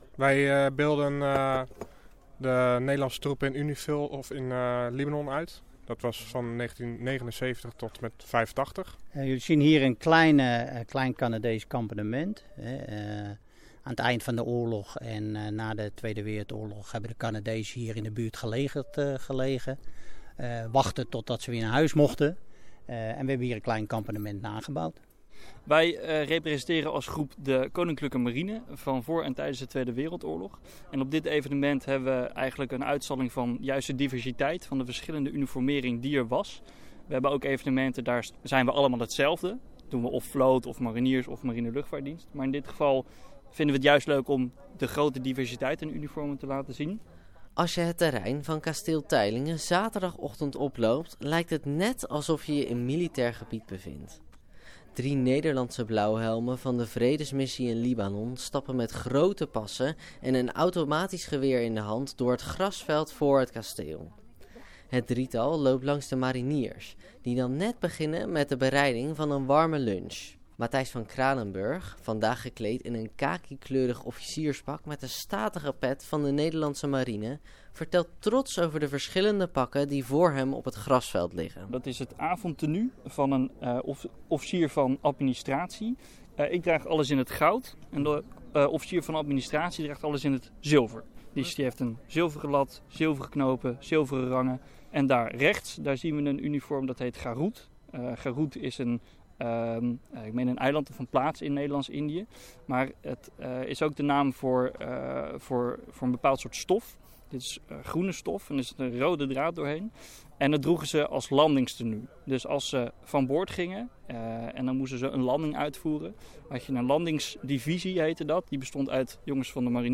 Dit weekend staat Kasteel Teylingen in het teken van ‘oorlog en vrede in de Bollenstreek’. Verschillende re-enactmentgroepen proberen de militairen en kampementen van die tijd zo goed mogelijk na te bootsen.